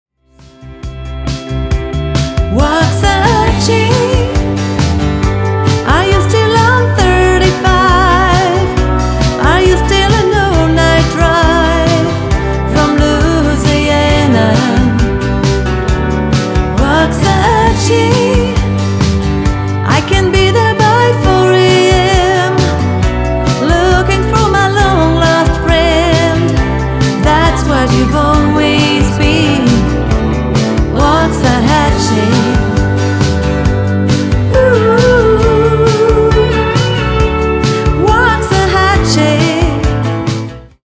Tour de chant 100% country.